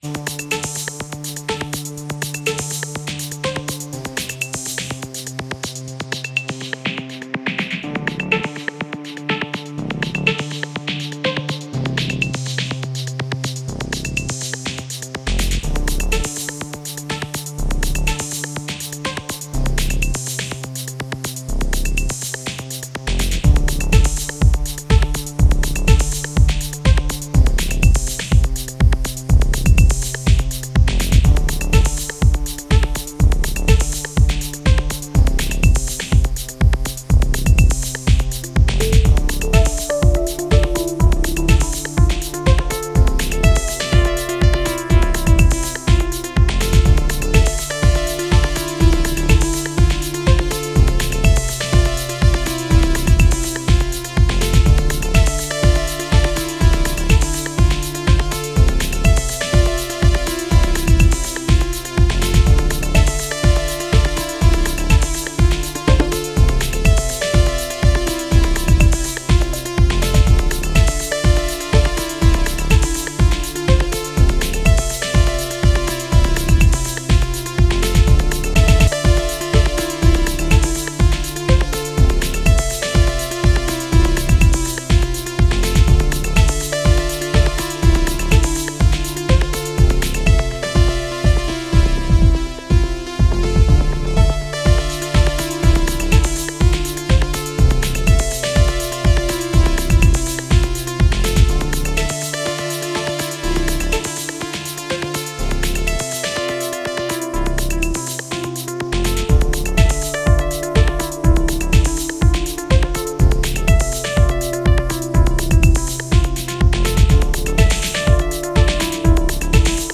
MC707 only jam.